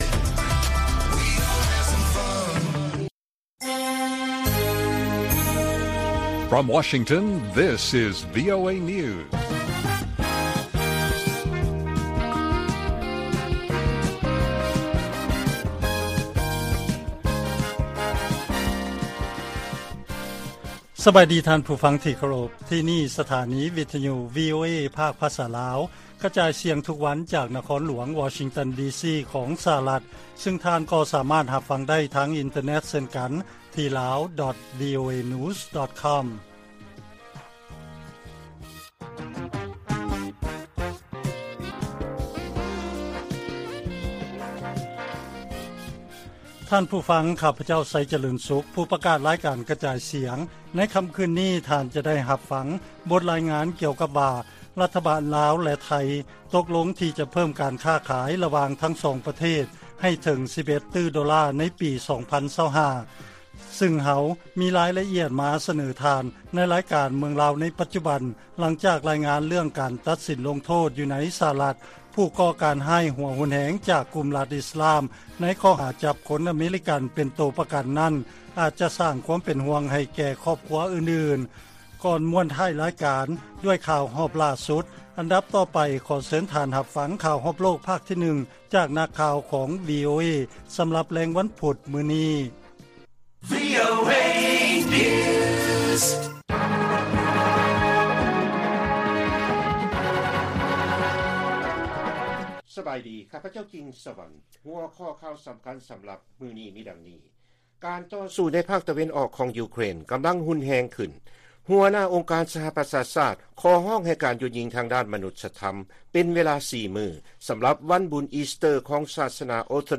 ລາຍການກະຈາຍສຽງຂອງວີໂອເອ ລາວ: ການຕໍ່ສູ້ໃນພາກຕາເວັນອອກຂອງຢູເຄຣນ ພວມຮຸນແຮງຂຶ້ນ